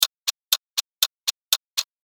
制限時間2.mp3